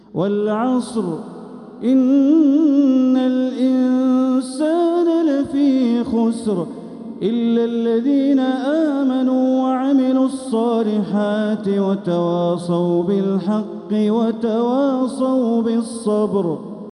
سورة العصر | مصحف تراويح الحرم المكي عام 1446هـ > مصحف تراويح الحرم المكي عام 1446هـ > المصحف - تلاوات الحرمين